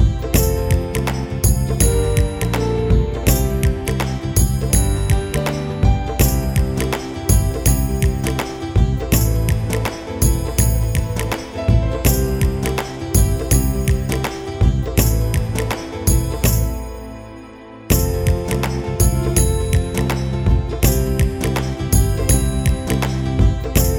no Backing Vocals Bollywood 5:20 Buy £1.50